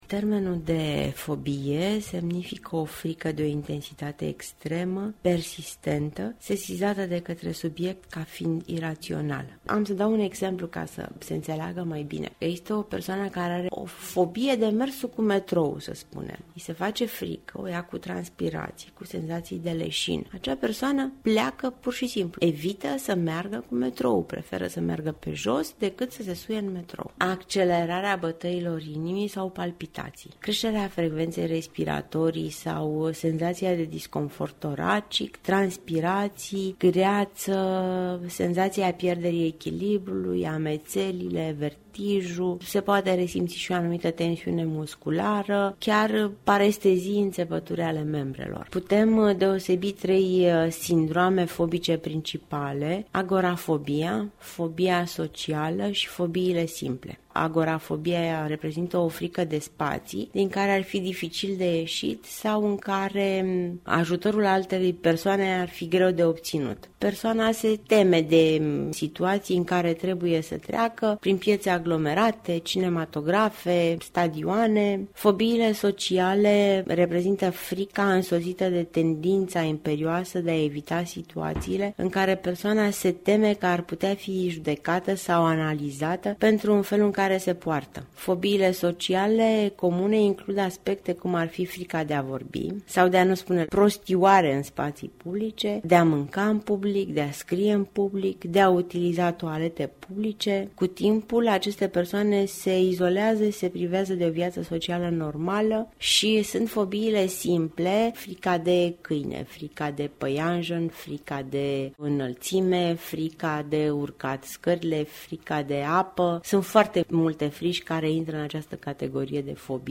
psiholog